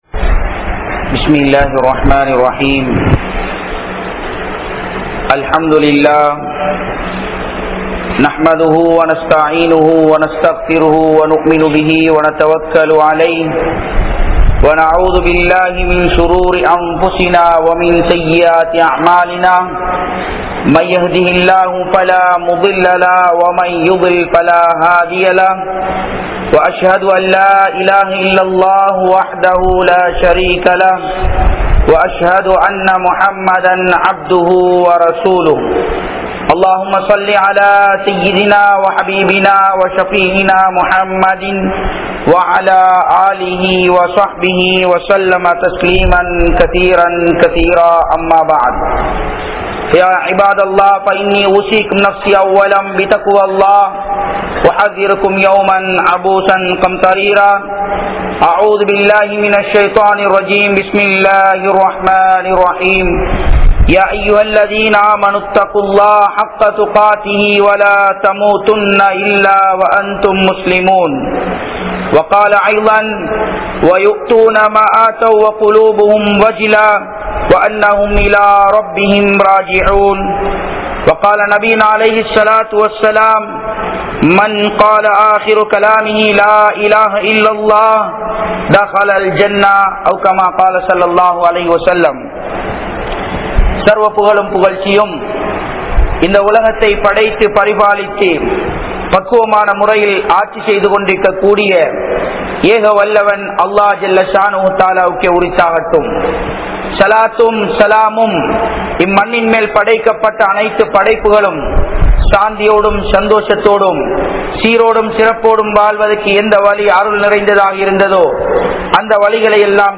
Manithanin Iruthi Mudivum Moochum (மனிதனின் இறுதி மூச்சும் முடிவும்) | Audio Bayans | All Ceylon Muslim Youth Community | Addalaichenai